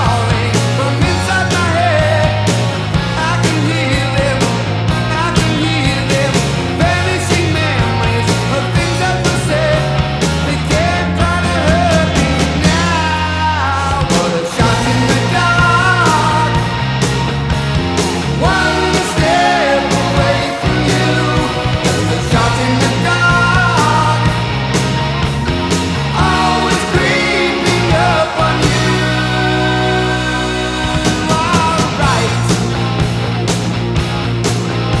Metal Rock Arena Rock Hard Rock
Жанр: Рок / Метал